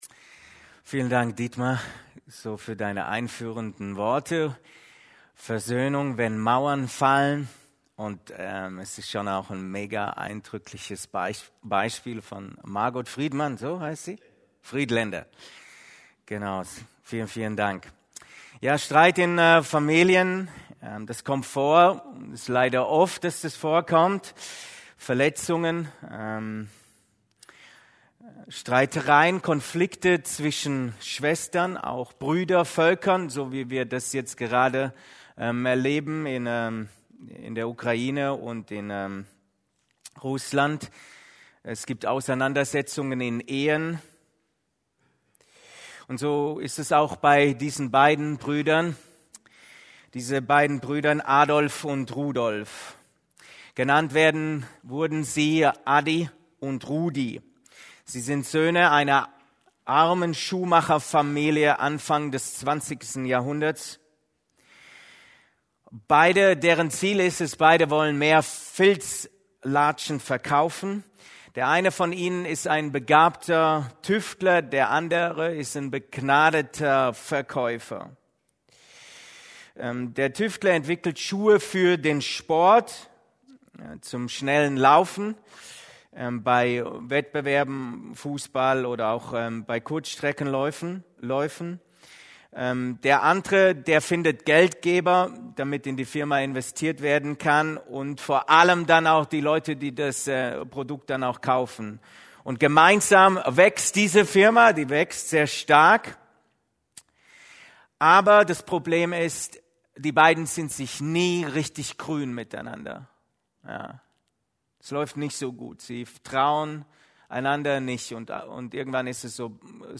Versöhnung – wenn Mauern fallen – Predigten: Gemeinschaftsgemeinde Untermünkheim